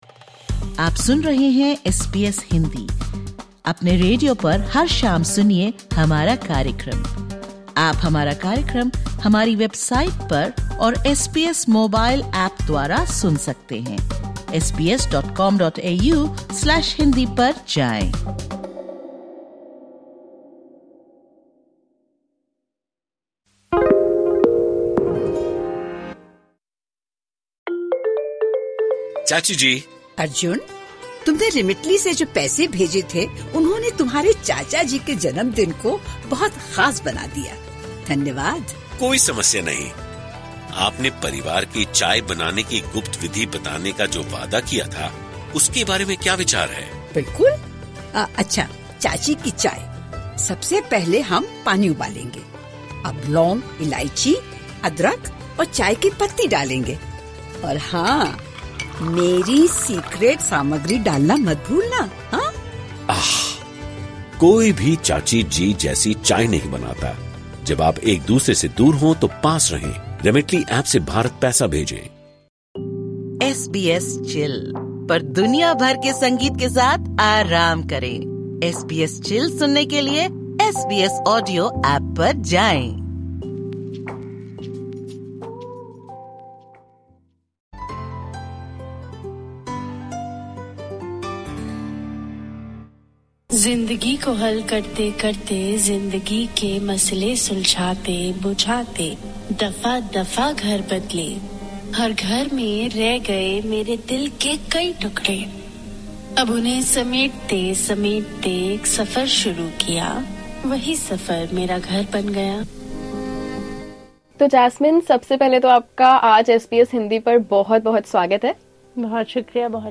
Tune in to this candid conversation to explore the journey of migrants seeking to find a home away from home.